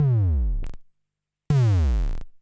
Index of /90_sSampleCDs/300 Drum Machines/Klone Dual-Percussion-Synthesiser/KLONE FILT NW8